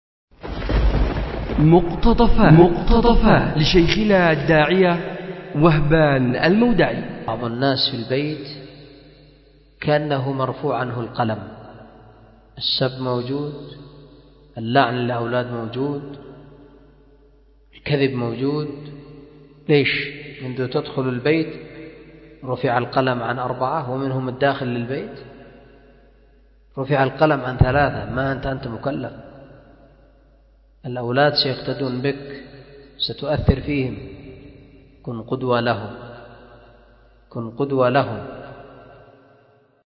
أُلقي بدار الحديث للعلوم الشرعية بمسجد ذي النورين ـ اليمن ـ ذمار 1444هـ